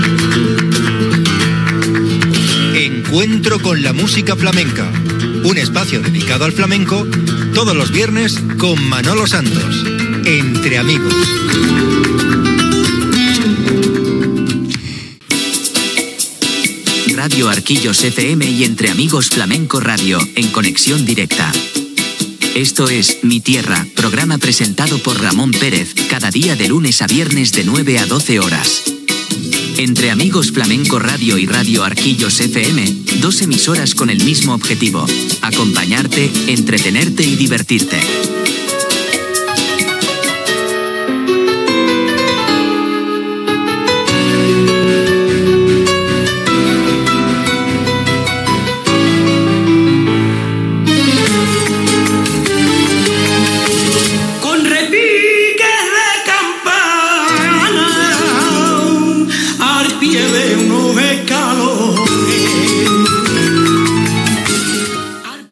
Promoció del programa "Encuentro con la música flamenca", identificació del programa i de la ràdio i tema musical
Musical